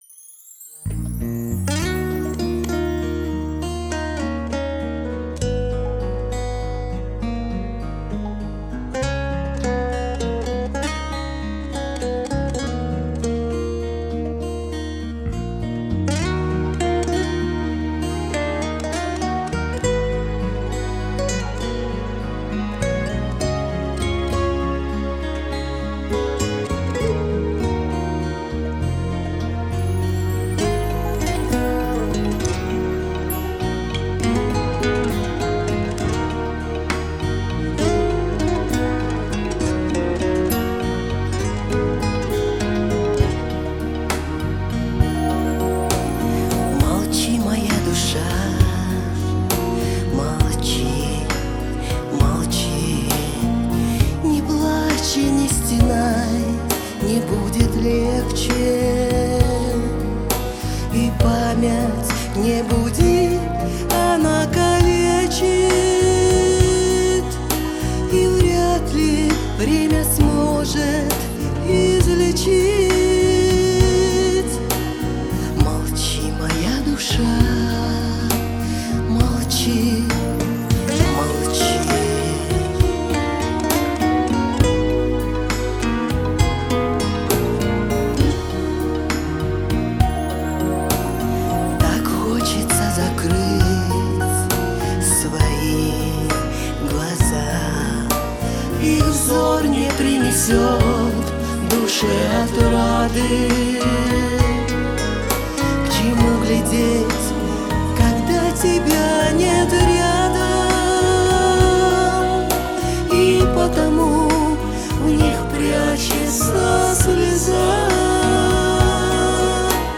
гитара, бэк-вокал